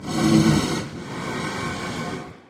Sound / Minecraft / mob / blaze / breathe1.ogg
breathe1.ogg